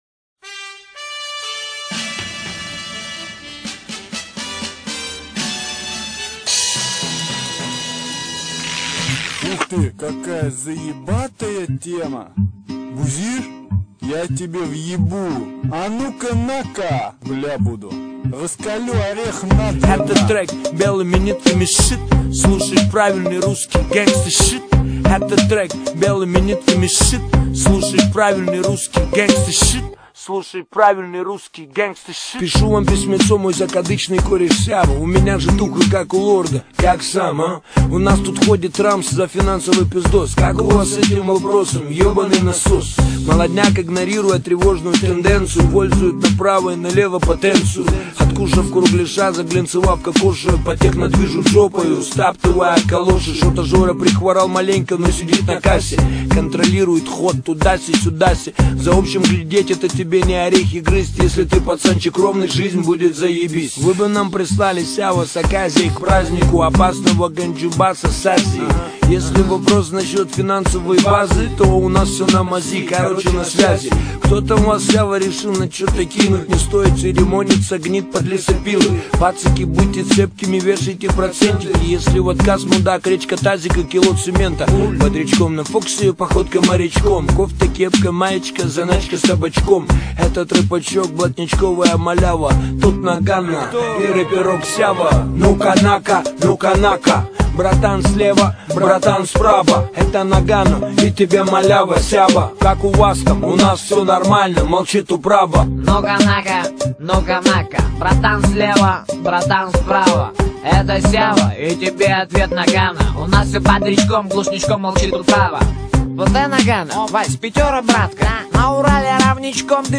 Категория: Hip-Hop - RAP